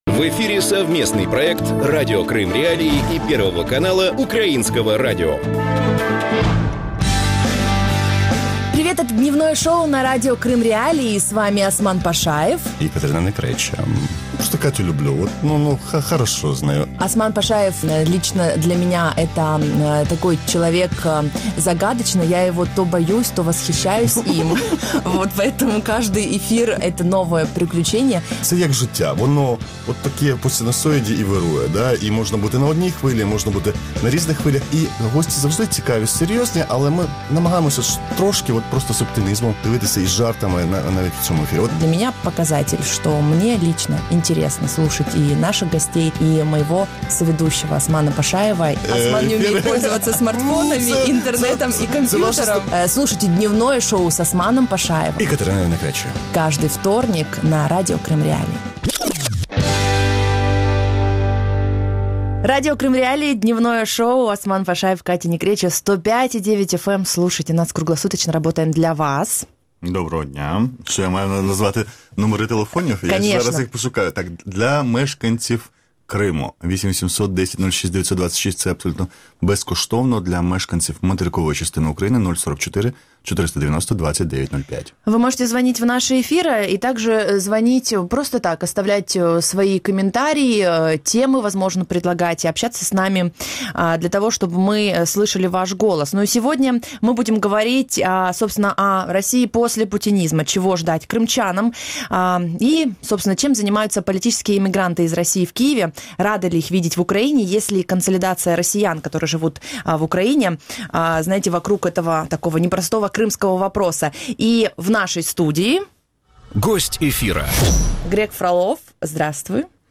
Есть ли консолидация россиян, которые живут в Украине вокруг крымского вопроса? Об этом в проекте «Дневное шоу» Радио Крым.Реалии с 12:10 до 12:40.